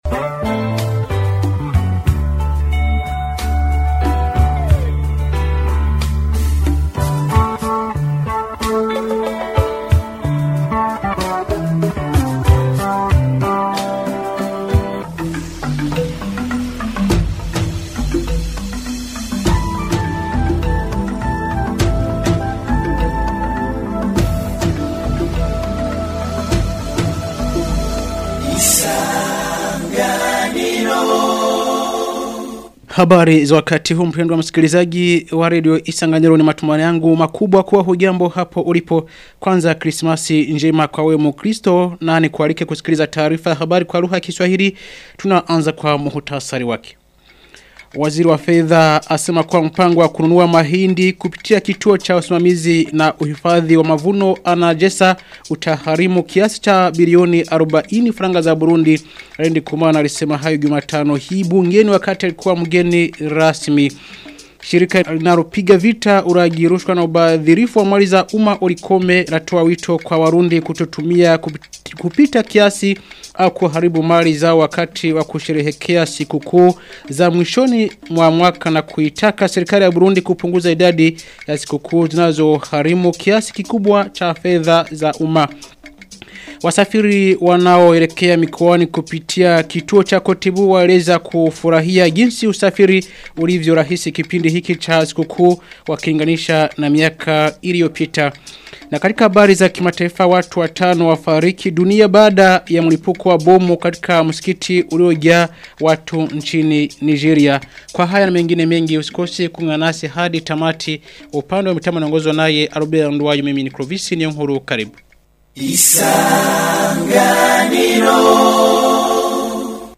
Taarifa ya habari ya tarehe 25 Disemba 2025